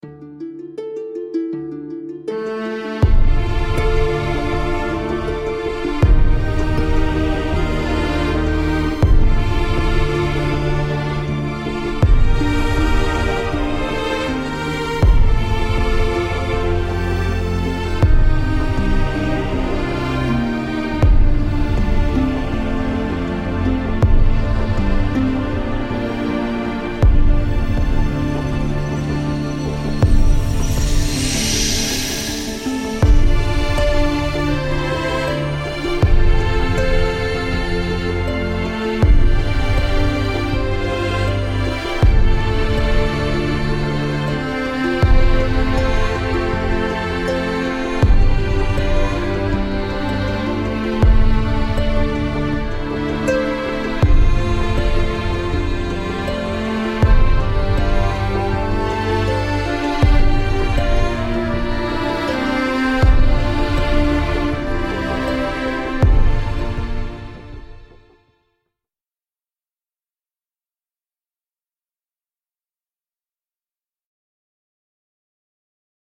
Artist: Instrumental,